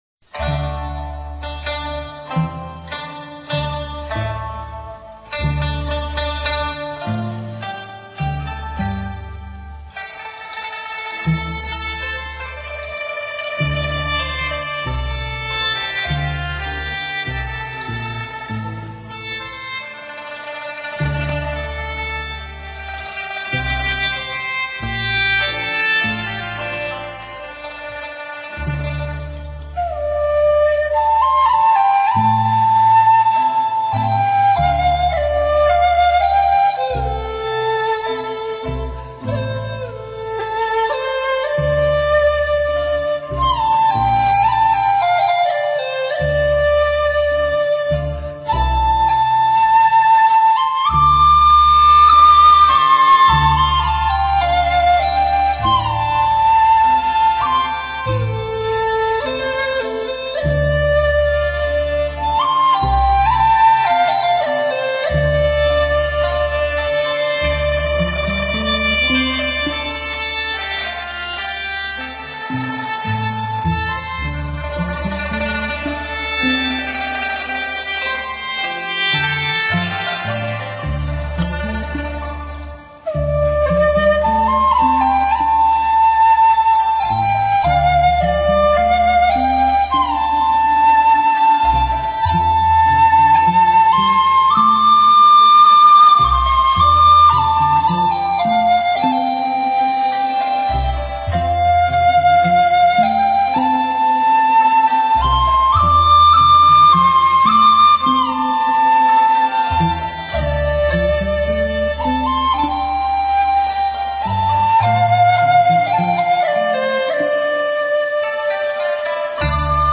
音乐类型：发烧民乐